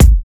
12kb - dre Kick.wav